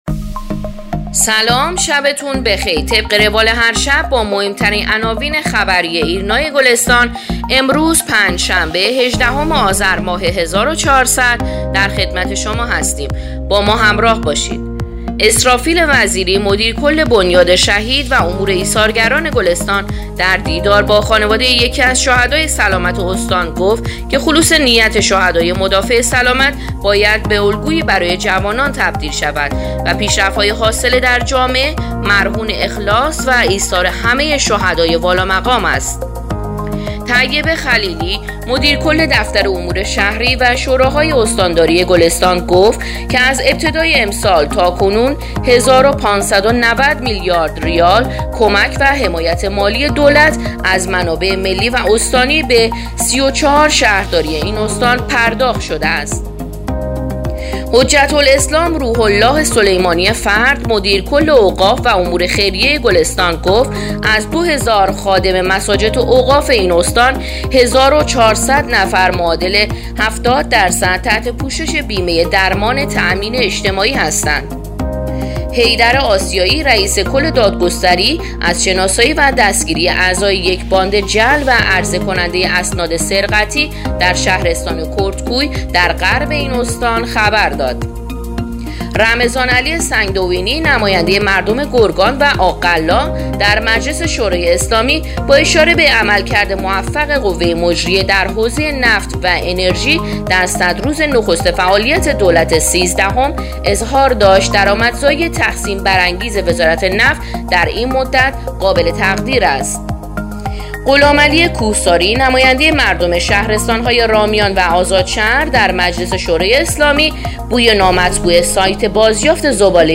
پادکست/ اخبار شبانگاهی هیجدهم آذر ایرنا گلستان